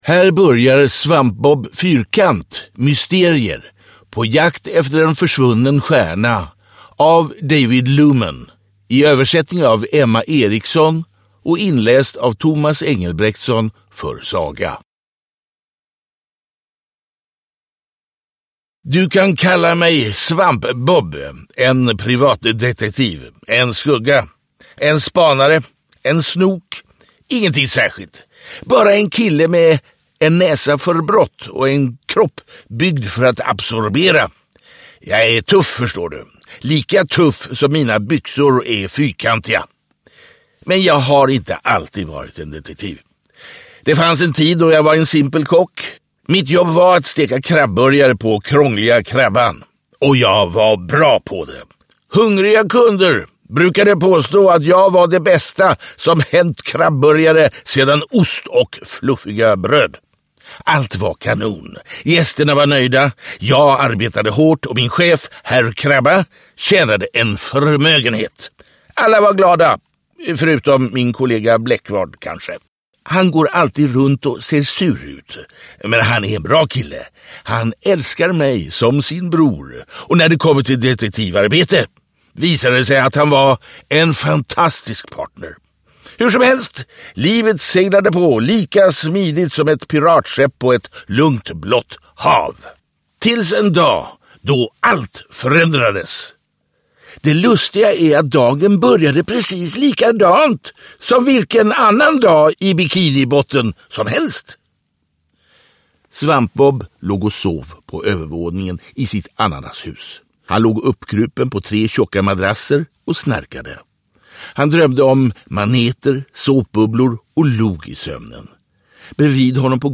SvampBob Fyrkant – Mysterier: På jakt efter en försvunnen stjärna – Ljudbok
6-9 år Barn & ungdom Njut av en bra bok Visa alla ljudböcker